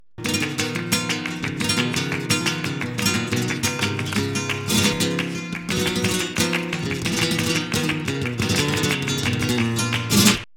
Tangos / 6 falsetas